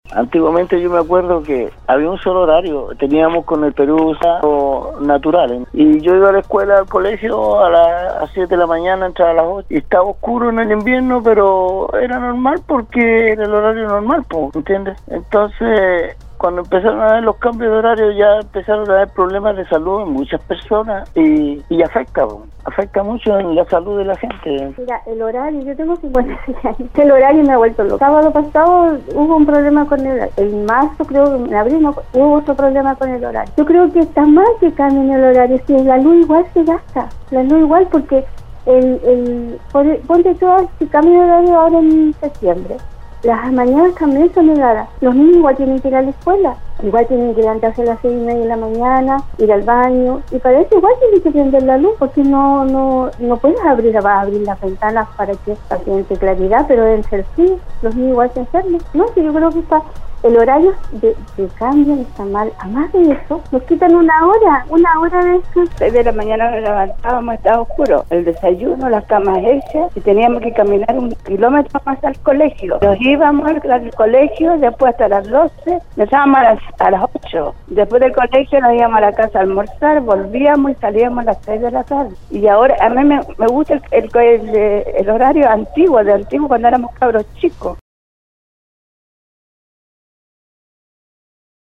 La mañana de este viernes, auditores de Nostálgica participaron en el programa Al Día, donde opinaron sobre una iniciativa que varios parlamentarios presentaron en el mes de agosto del 2018, donde se busca eliminar el cambio de horario, este proyecto ingreso al senado para fijar en uso de diversos estándares UTC y así regular la hora oficial en el territorio nacional.